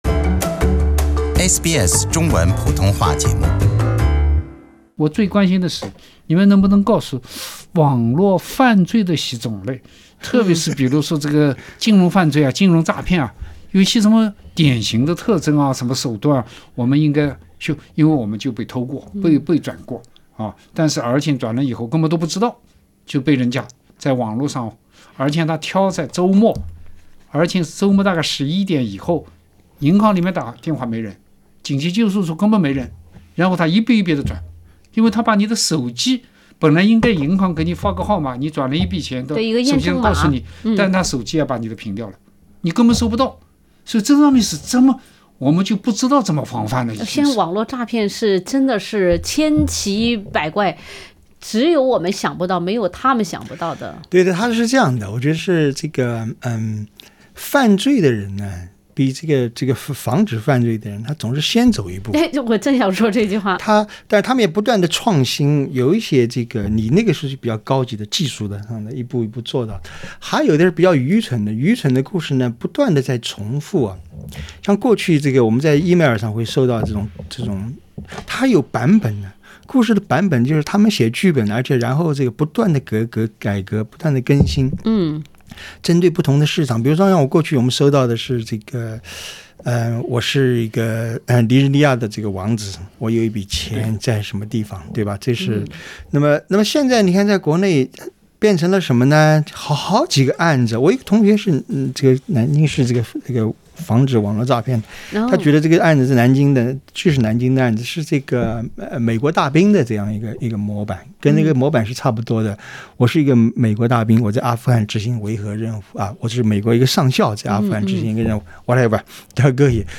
欢迎收听SBS 文化时评栏目《文化苦丁茶》，大数据的危机（第3集）：网络帮你记住一切，你真的愿意吗？